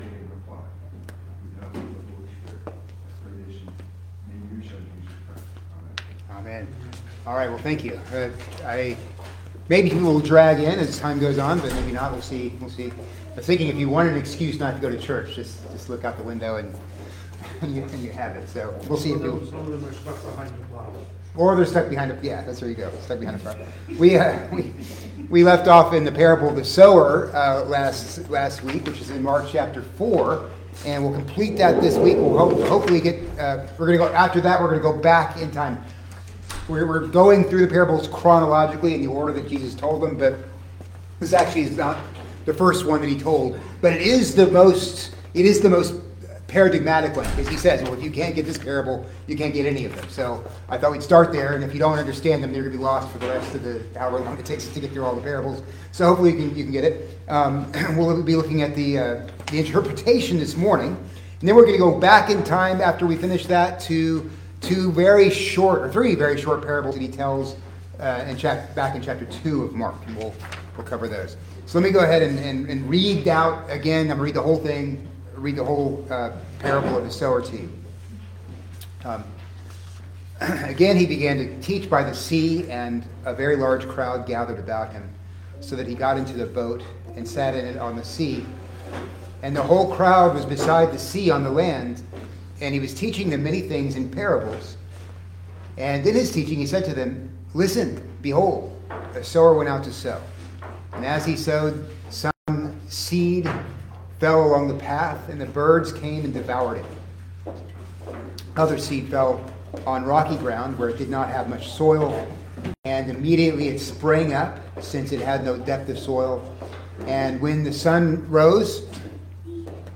Christian Education – February 16, 2025